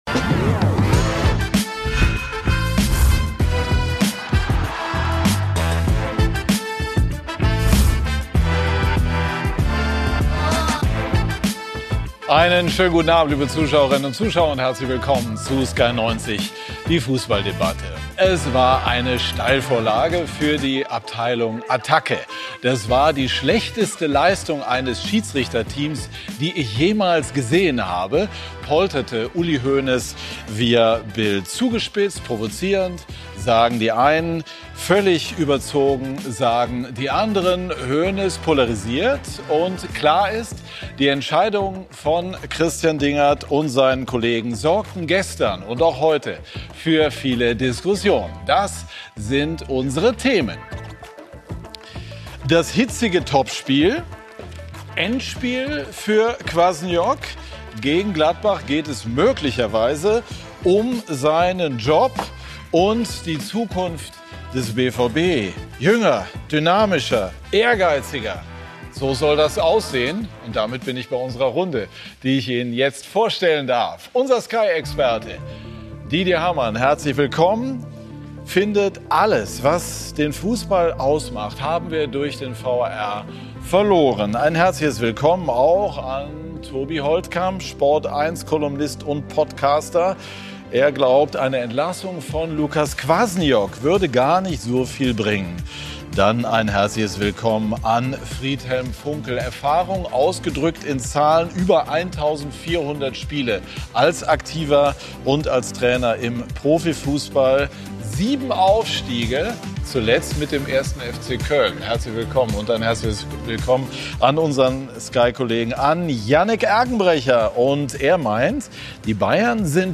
Kontrovers, unterhaltsam, meinungsbildend – mit Sky90 präsentiert Sky den umfassendsten Fußball-Live-Talk Deutschlands. Immer sonntags ab 18:00 Uhr begrüßt Moderator Patrick Wasserziehr kompetente Gäste im Sky Studio.